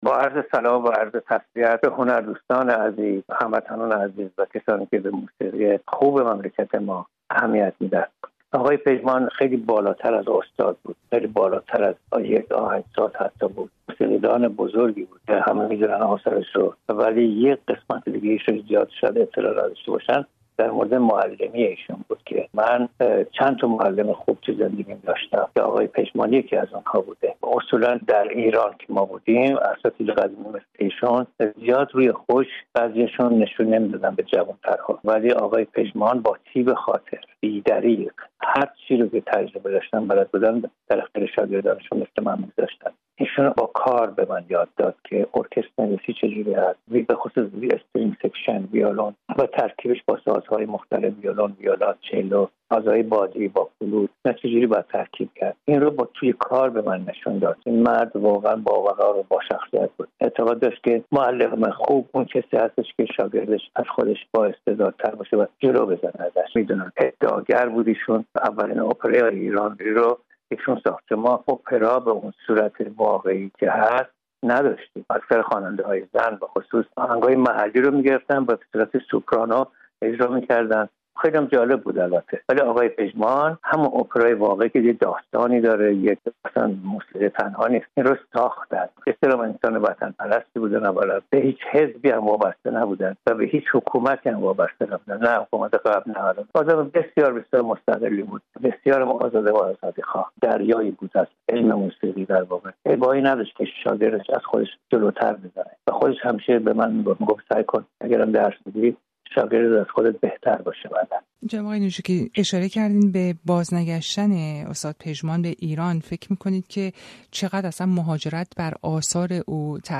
تأثیر احمد پژمان بر موسیقی ایران در گفت‌وگو با صادق نجوکی
با صادق نجوکی دیگر آهنگساز صاحب نام ایرانی در سبک موسیقی پاپ ایران مقیم لس‌آنجلس گفت‌وگو کرده و از زوایای زندگی و شخصیت احمد پژمان پرسیده‌ایم.